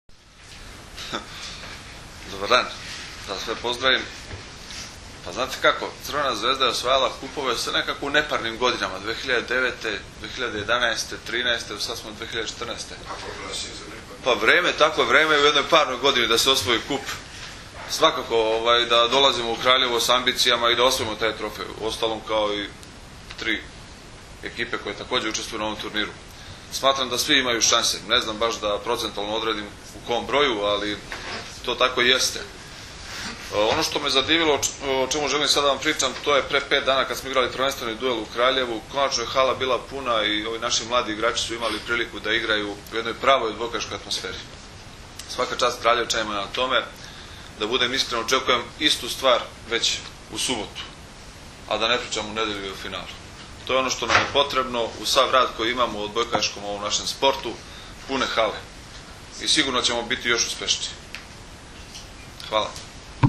U prostorijama Odbojkaškog saveza Srbije danas je održana konferencija za novinare povodom Finalnog turnira 49. Kupa Srbije u konkurenciji odbojkaša, koji će se u subotu i nedelju odigrati u Hali sportova u Kraljevu.